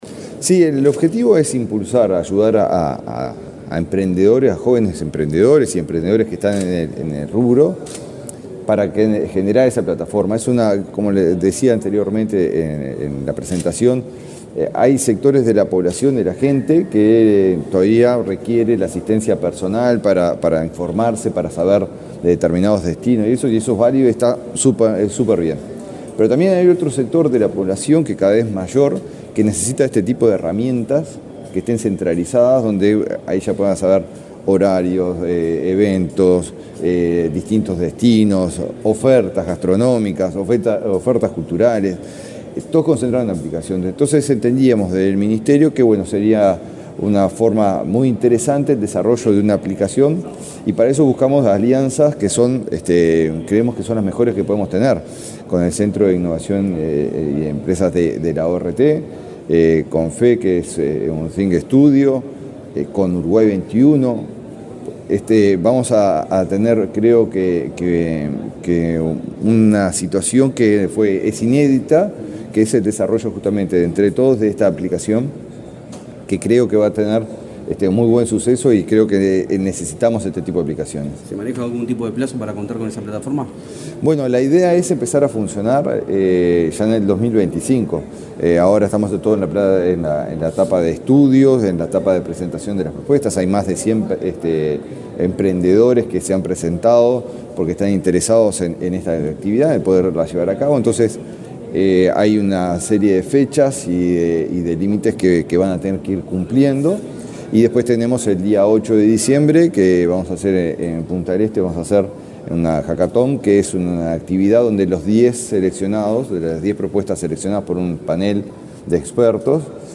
Declaraciones del ministro de Turismo, Eduardo Sanguinetti
Declaraciones del ministro de Turismo, Eduardo Sanguinetti 11/11/2024 Compartir Facebook X Copiar enlace WhatsApp LinkedIn El ministro de Turismo, Eduardo Sanguinetti, dialogó con la prensa, este lunes 11 en el Laboratorio Tecnológico del Uruguay, luego de participar en el lanzamiento de iUruguay Open Call, una iniciativa con la que se busca desarrollar una plataforma digital de información turística de Uruguay.